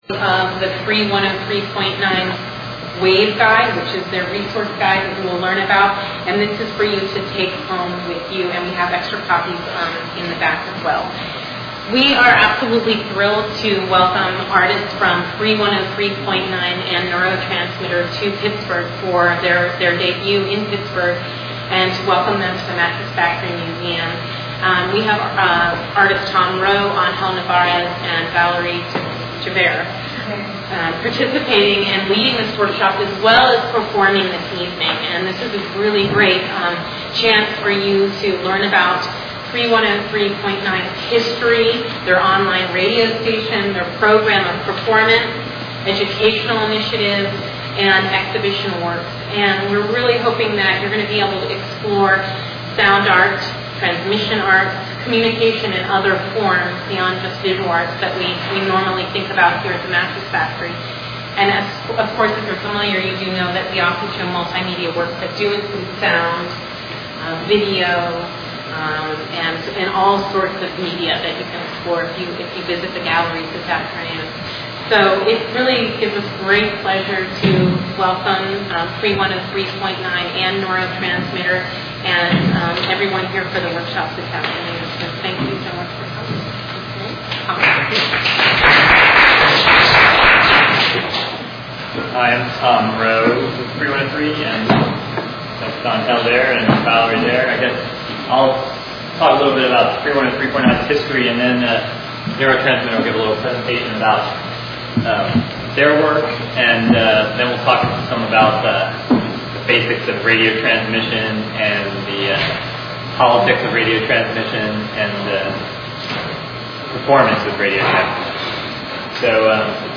Mattress Factory Radio Lab: Dec 03, 2005: 1pm- 4pm
The neuroTransmitter part begins, but is cut off on this recording.